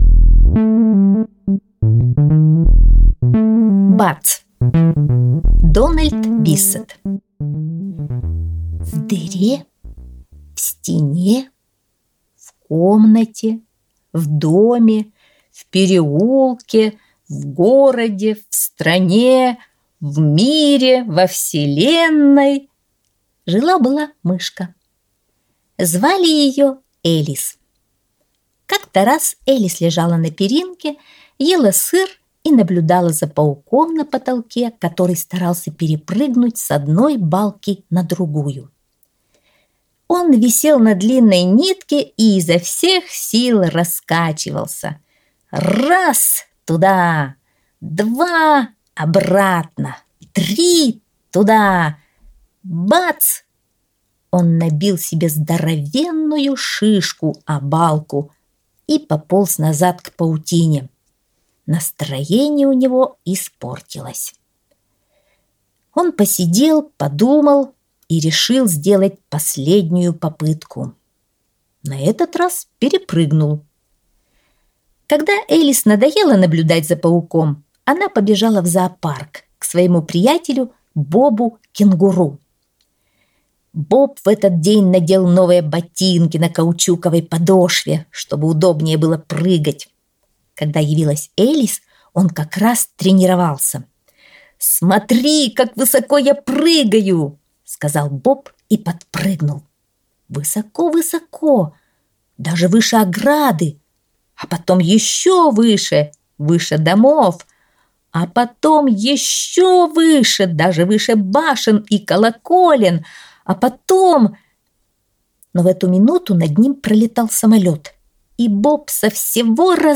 Аудиосказка «Бац»